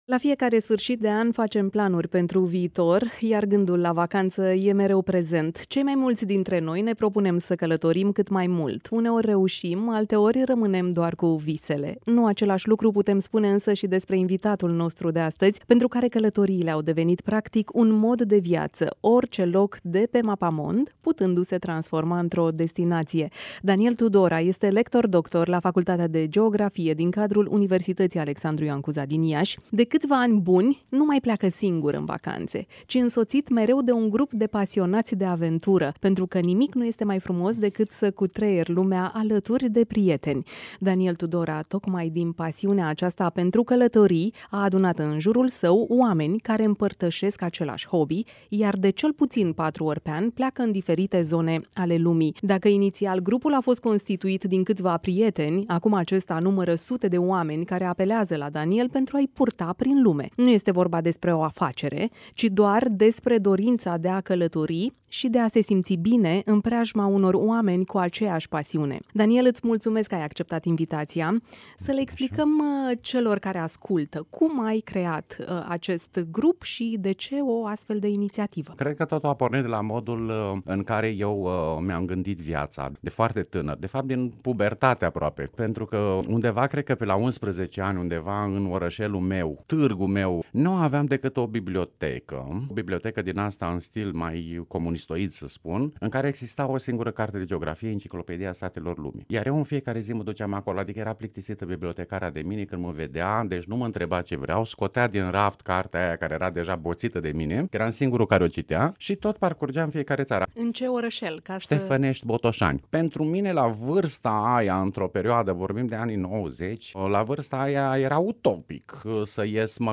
(INTERVIU/AUDIO) Un altfel de turism: pasiune și prieteni, rețeta unui profesor ieşean. Emisiunea "Week-end cu prieteni" (22.12.2019) - Radio Iaşi – Cel mai ascultat radio regional - știri, muzică și evenimente